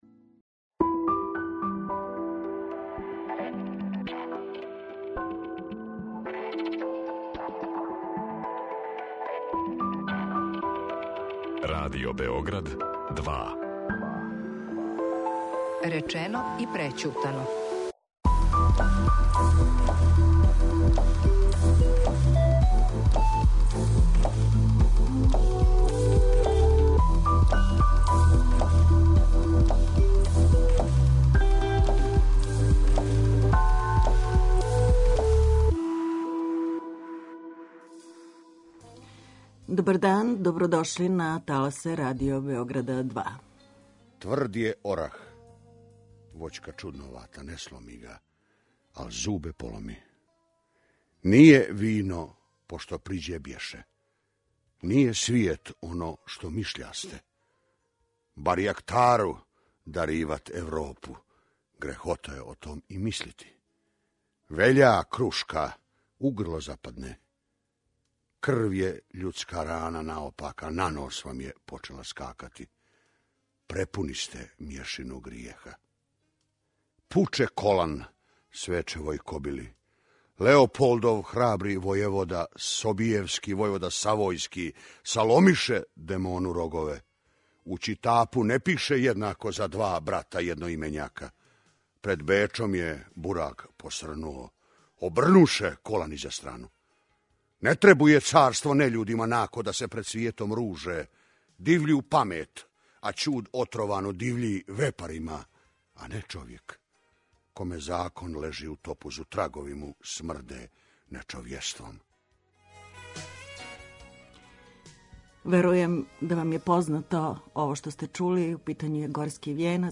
Подсетићемо се неких стихова из „Горског вијенца" у тумачењу Данила Лазовића.